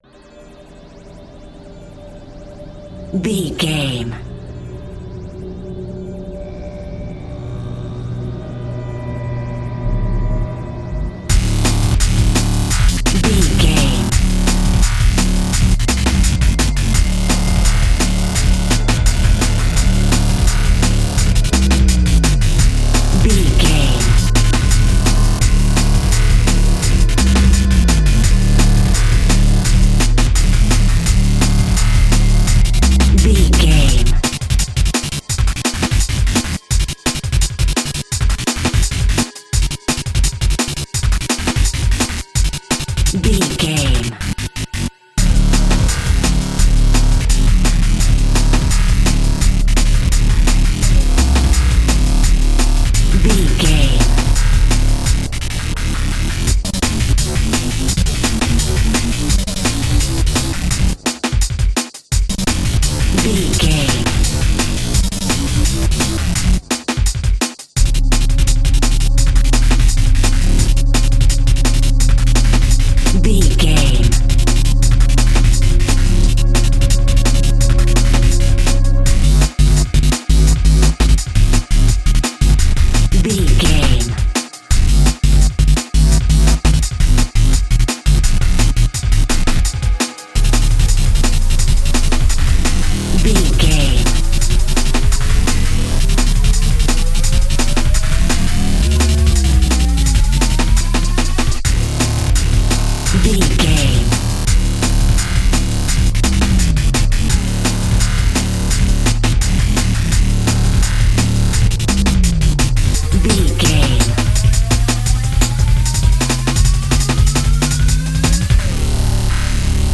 Aeolian/Minor
D
synthesiser
electric guitar
drum machine